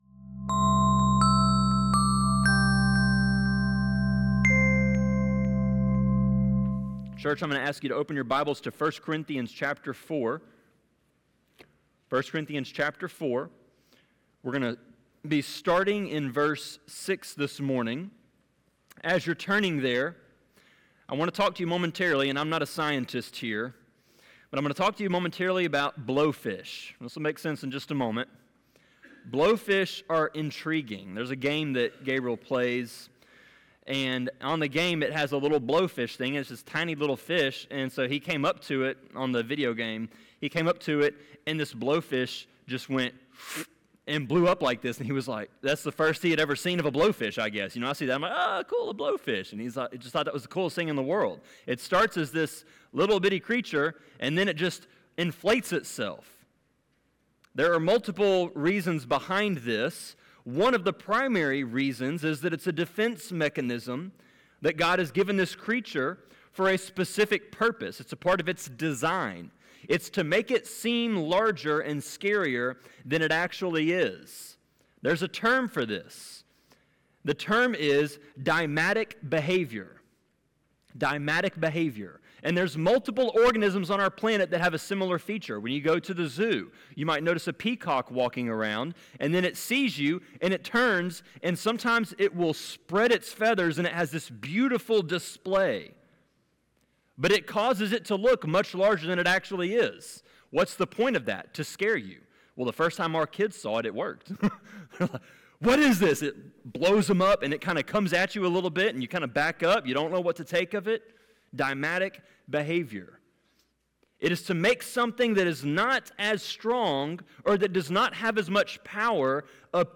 Sermon-23.4.2.m4a